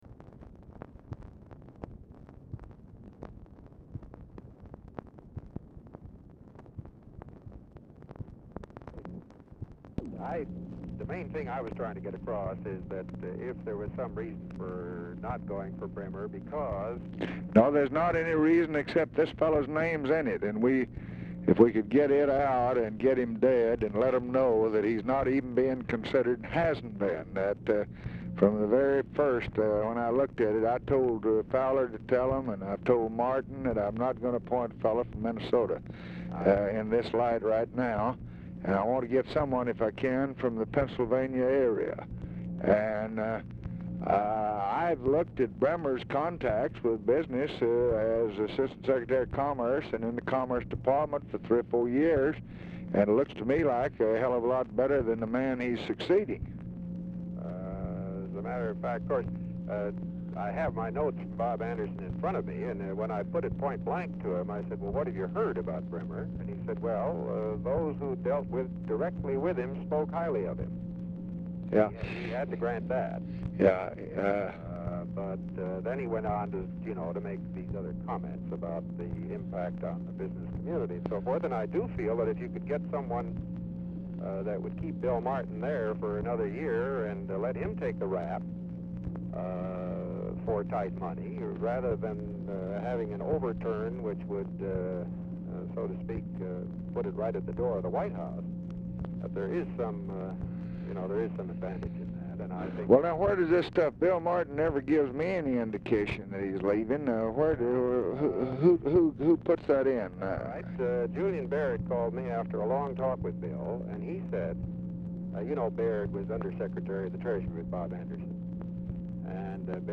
Telephone conversation # 9627, sound recording, LBJ and WALTER HELLER, 2/9/1966, 1:45PM
Format Dictation belt
Location Of Speaker 1 Oval Office or unknown location
Specific Item Type Telephone conversation